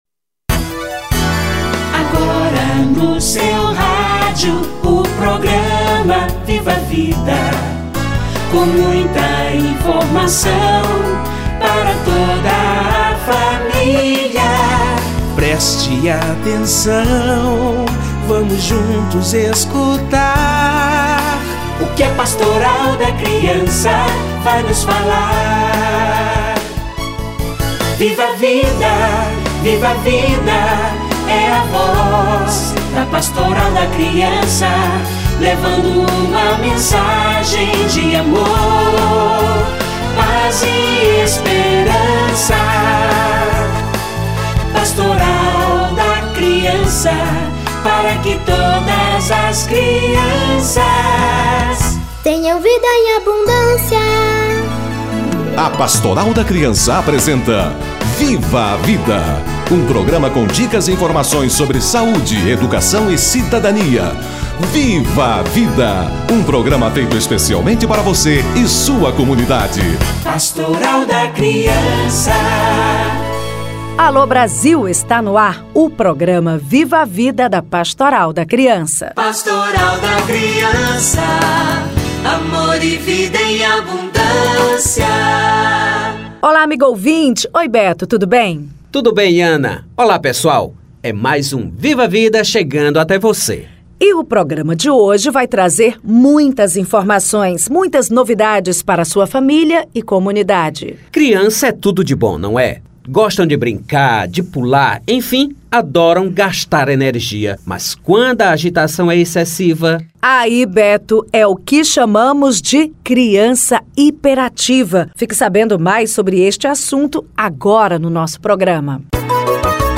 Crianças hiperativas - Entrevista